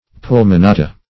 Pulmonata \Pul`mo*na"ta\, n. pl.